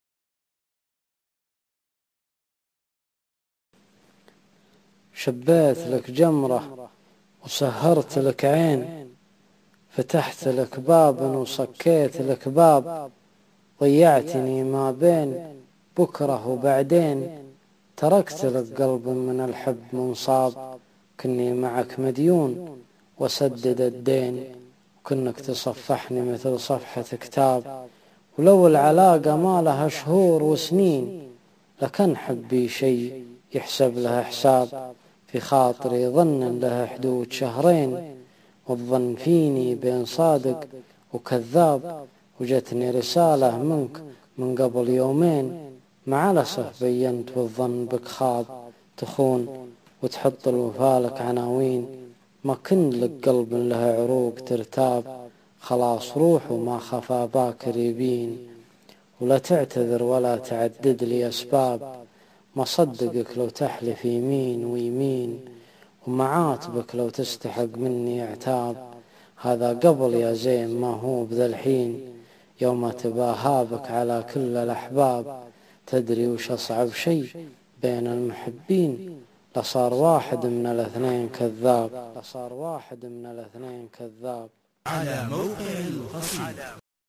shbyt_bdwn_mwsyka5.mp3